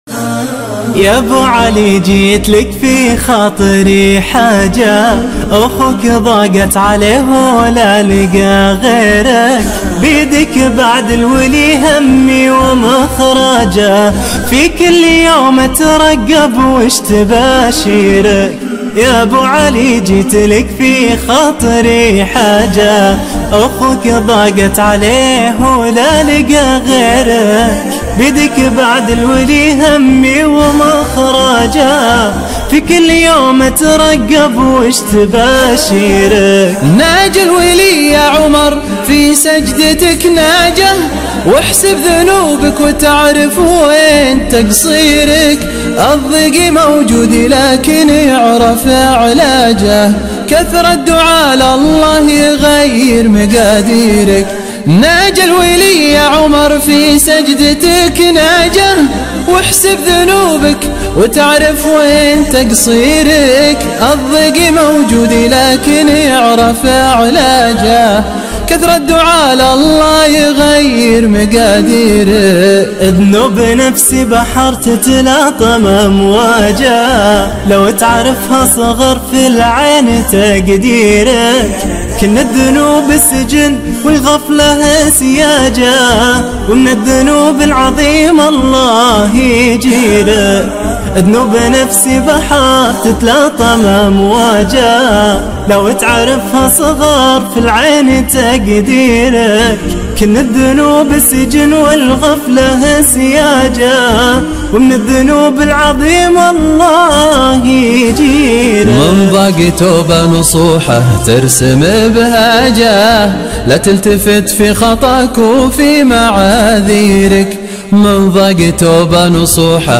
افتراضي افضل نشيد اسلامي بدون ايقاع mp3